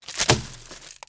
TrashCan2.wav